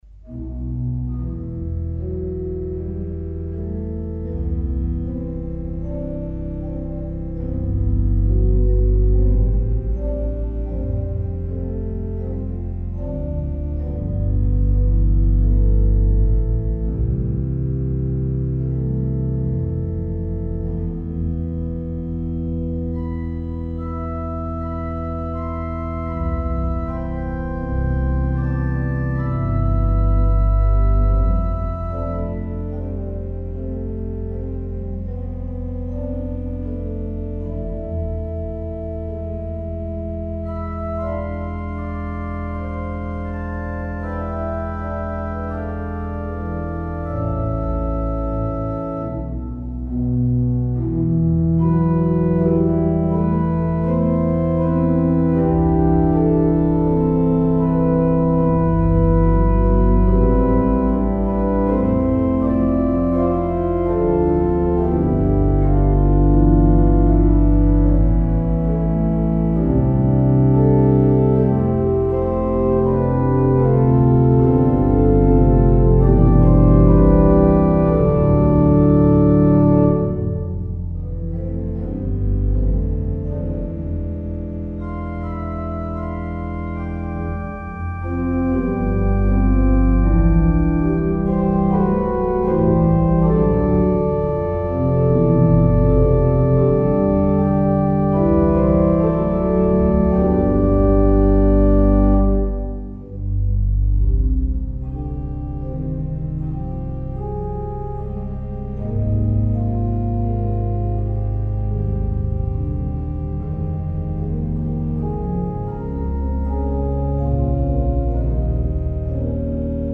Pigekor